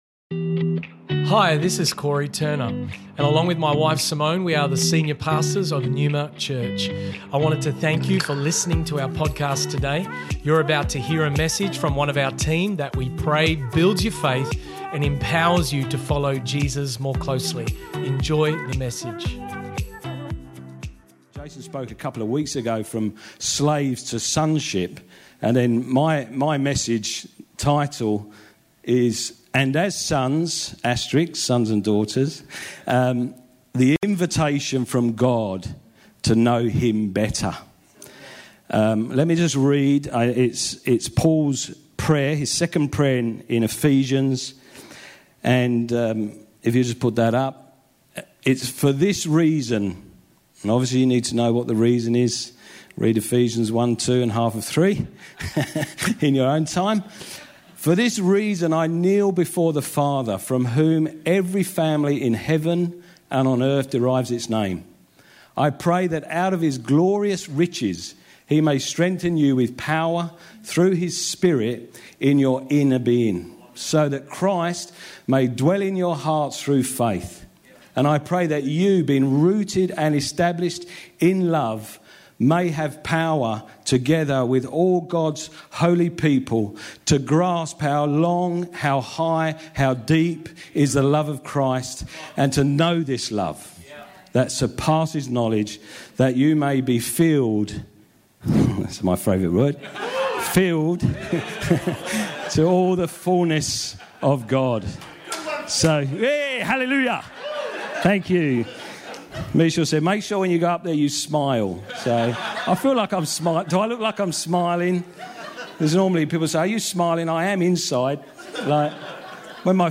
This message was originally preached on 11 July, 2021 at NEUMA Church Perth.